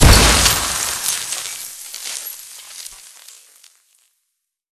fx_explosion_mine_cryo_01.wav